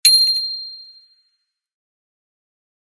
bicycle-bell_15
bell bicycle bike clang contact ding glock glockenspiel sound effect free sound royalty free Sound Effects